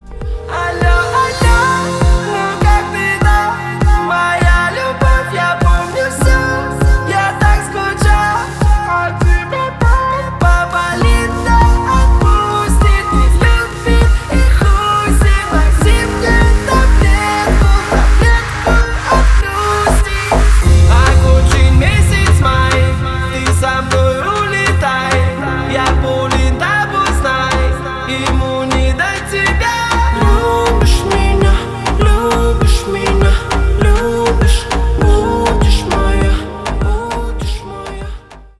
Поп Музыка
клубные